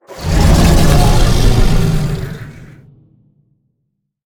Sfx_creature_hiddencroc_roar_02.ogg